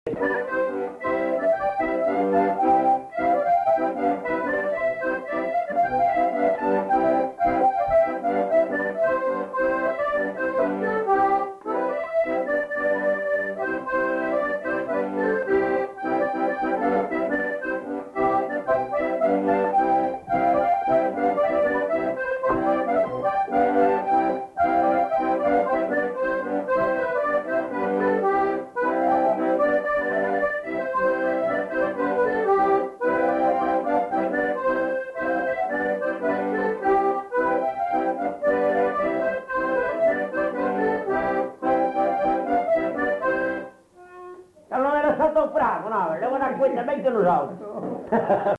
interprété à l'accordéon diatonique
enquêtes sonores